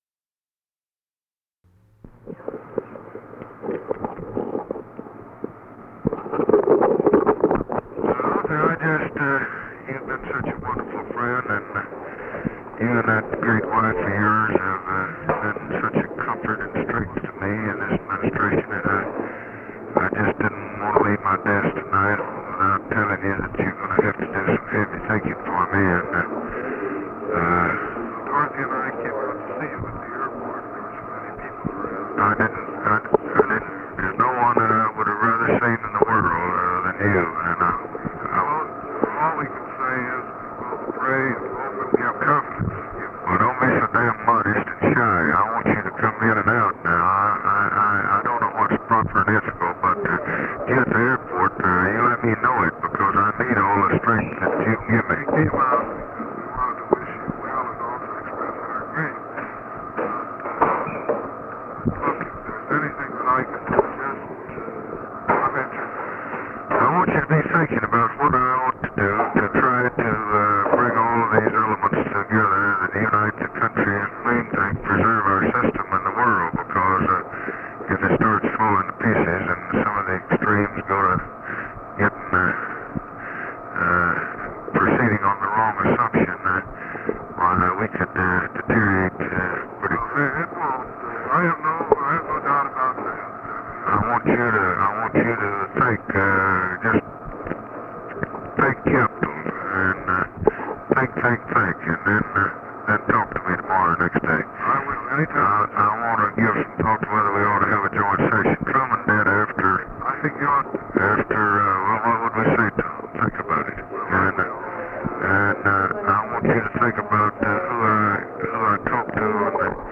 Conversation with ARTHUR GOLDBERG, November 22, 1963
Secret White House Tapes